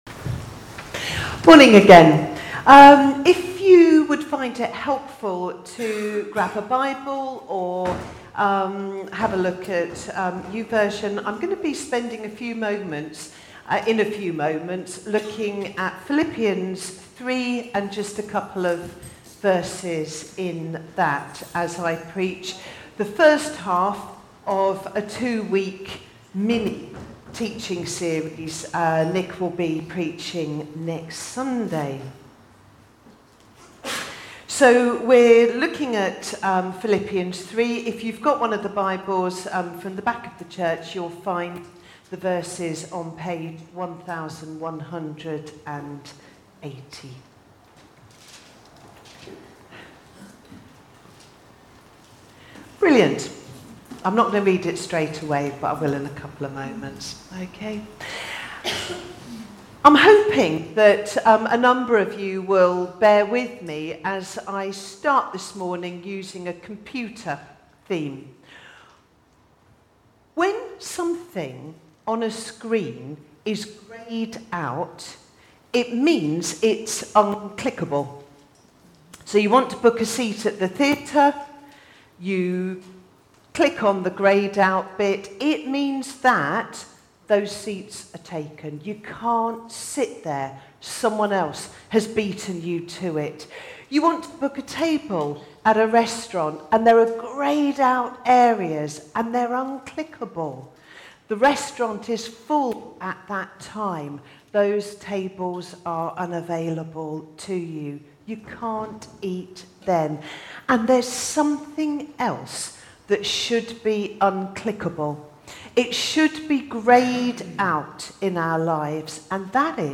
A message from the series "Stand Alone Sermons 2025."